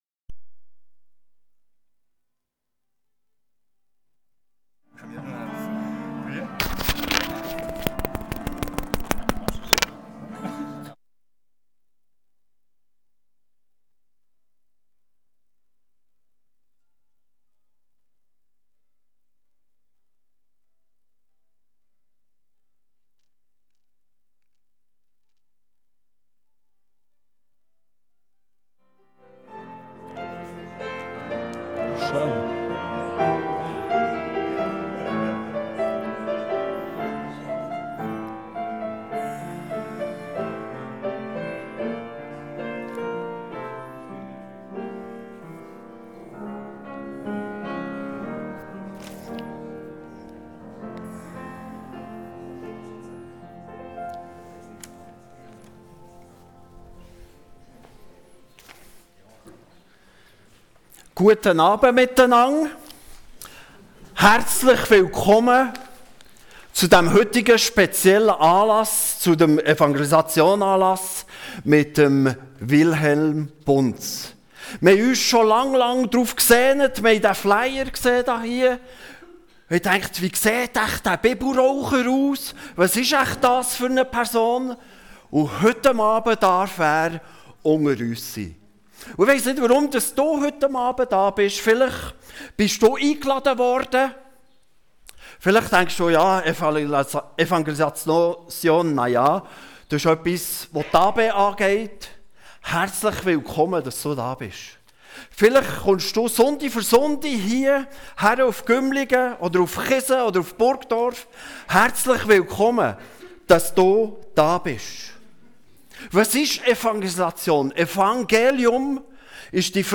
Predigt Download